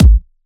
VFH3 128BPM Wobble House Kit
VFH3 128BPM Wobble House Kick.wav